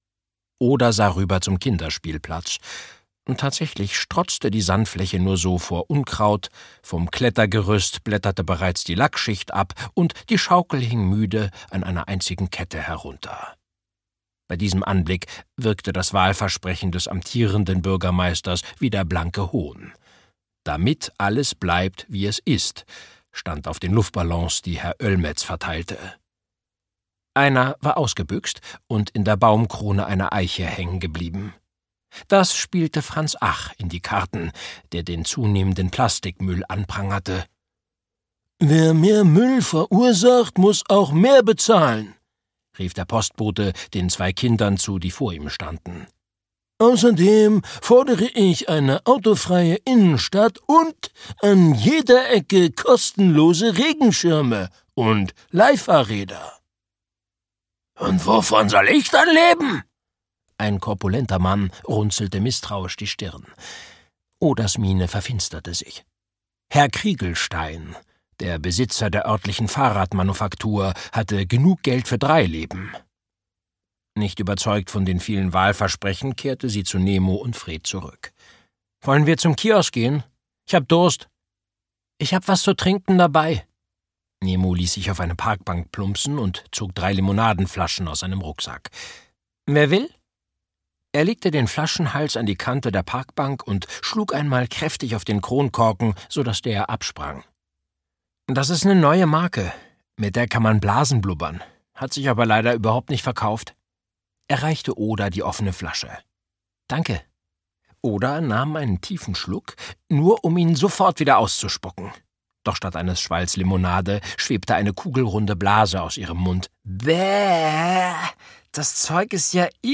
Hörbuch Bitte nicht öffnen 5: Magic!, Charlotte Habersack.